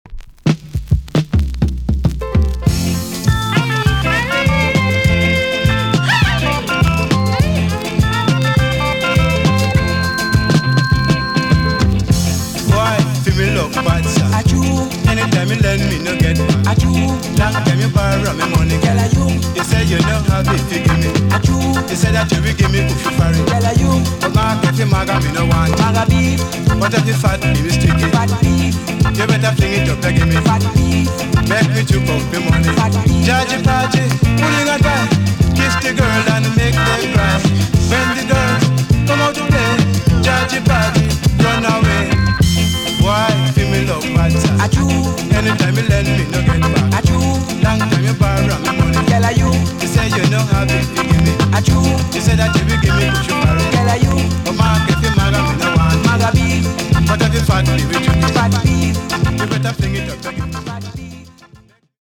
EX- 音はキレイです。
UK , SOCA
NICE VOCAL TUNE!!